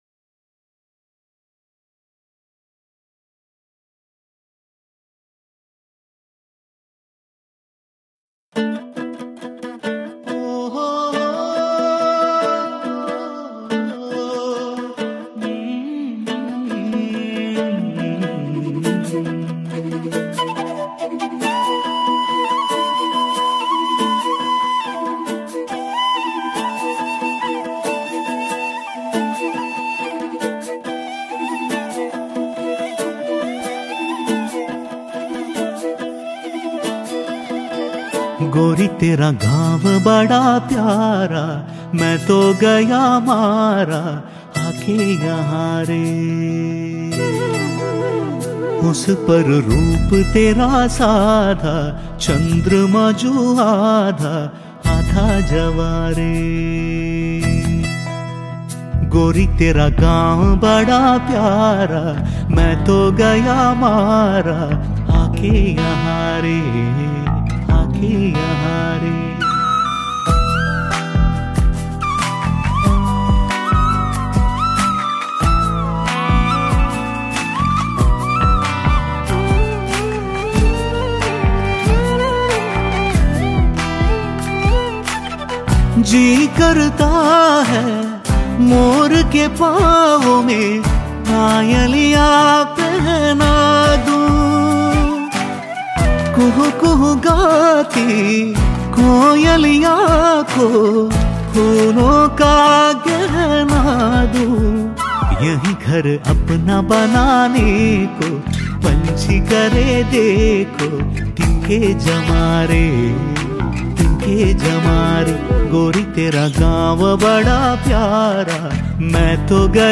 Bamboo Flutes
Guitar, Mandolin & Ukulele
Drone